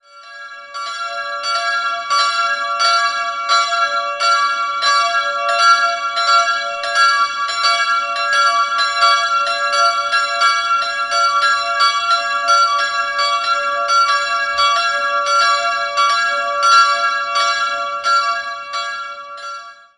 2-stimmiges Kleine-Terz-Geläute: d'''-f'''